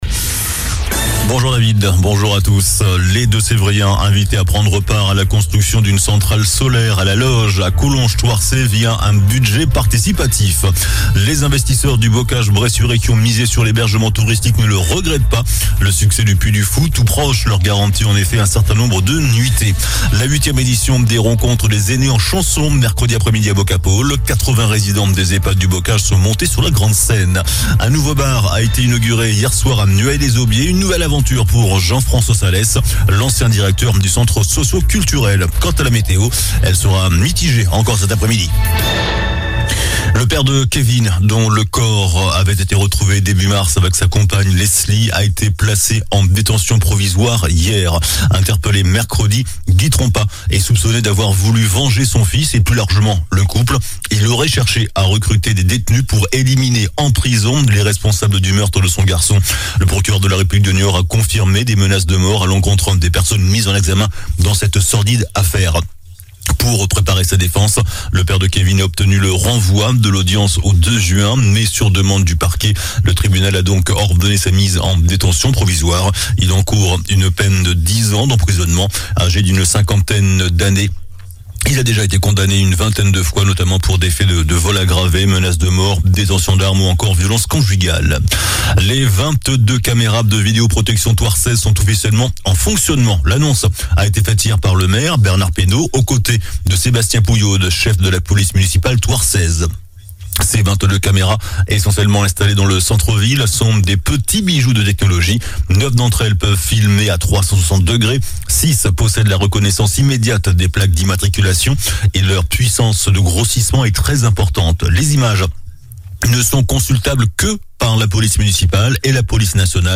JOURNAL DU VENDREDI 05 MAI ( MIDI )